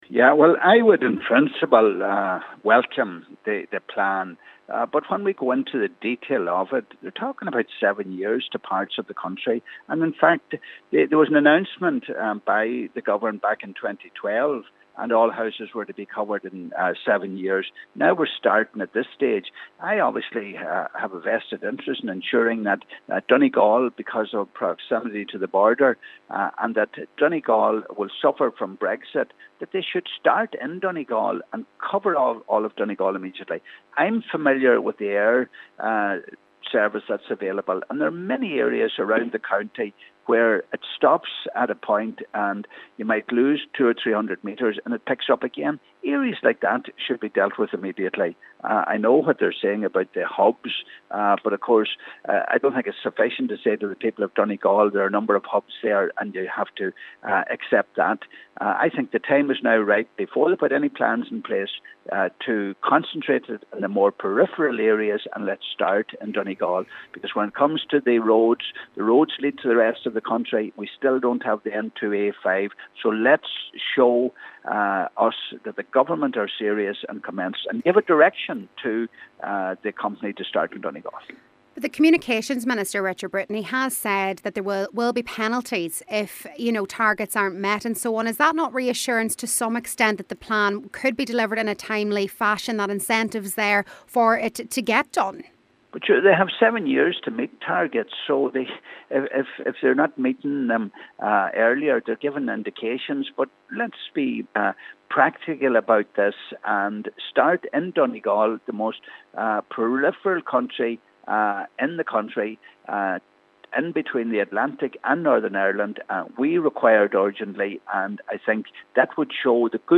He is calling on the county to be prioritised to ensure a swift delivery of the plan: